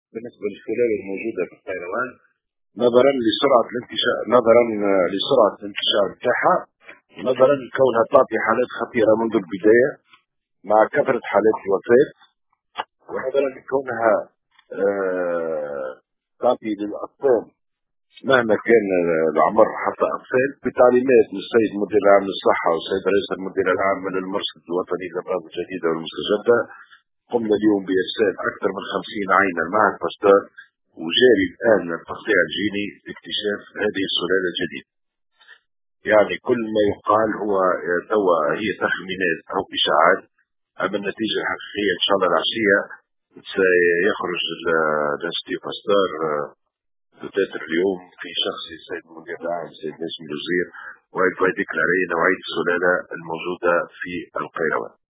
قامت الإدارة الجهوية للصحة بالقيروان بإرسال 50 عينة لإخضاعها للتقطيع الجيني لتحديد نوع السلالات الموجودة من فيروس كورونا في الجهة، بحسب ما أعلن عنه المدير الجهوي للصحة بالقيروان، محمد رويس، في تصريح للجوهرة أف أم، اليوم الأربعاء.